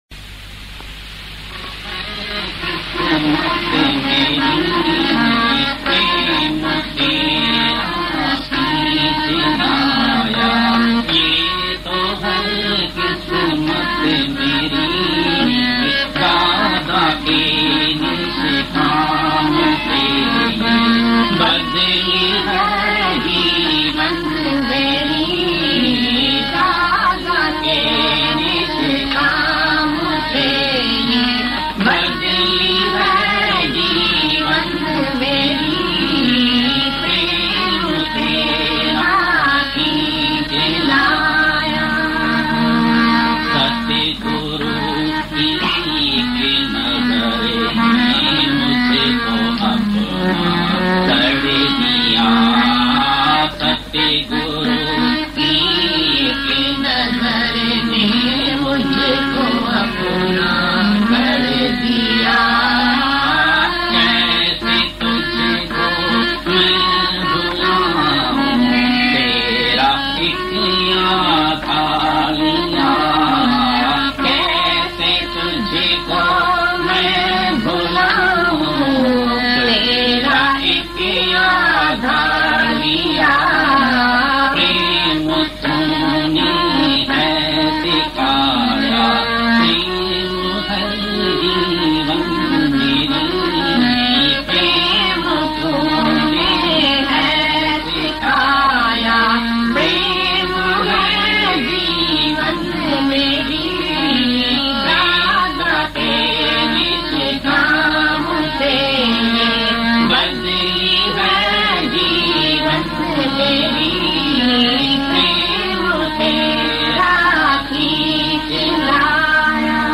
Prem Tera Khich Laaya Ye To Hai Kismat Meri Bhajan | प्रेम तेरा खींच लाया ये तो है किस्मत मेरी भजनDivine Geeta Bhagwan Hindi Bhajans